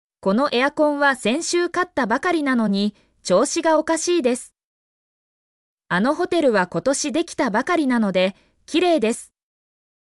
mp3-output-ttsfreedotcom-18_ewKaBECN.mp3